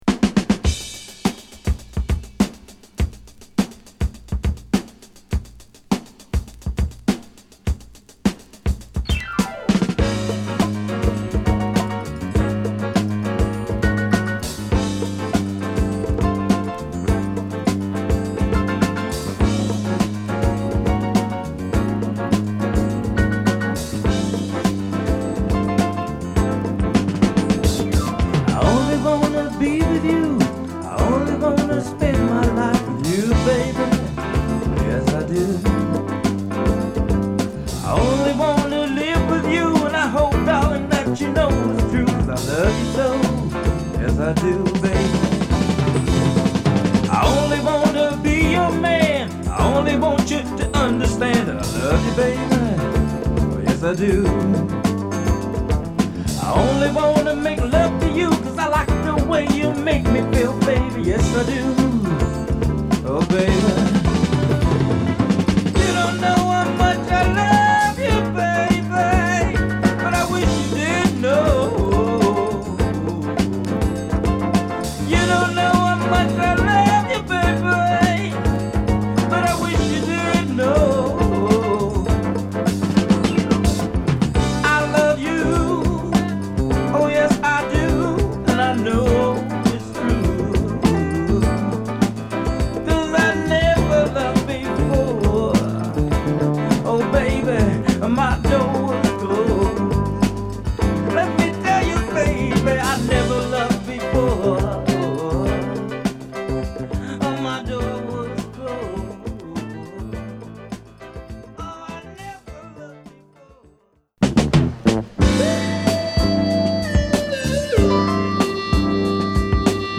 ブレイク満載のナイス・ジャズ・ファンク！